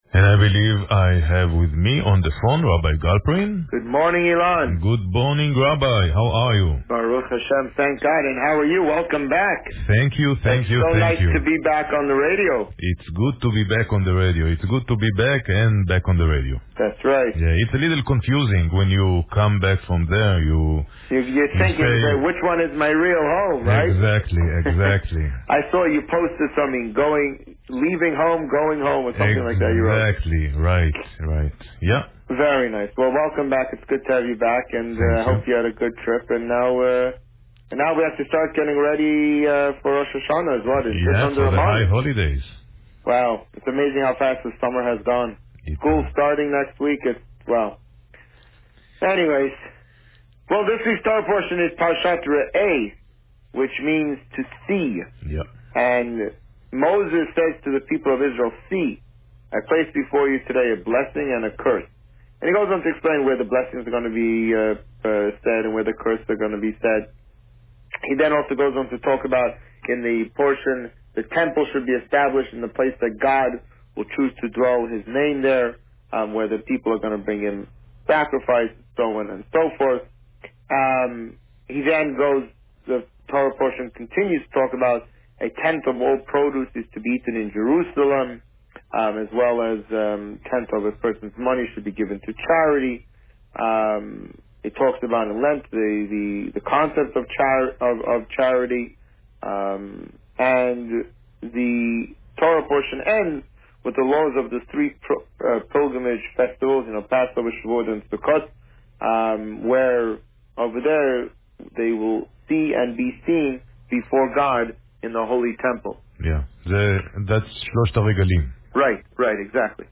This week, the Rabbi spoke about Parsha Re'eh. Listen to the interview here.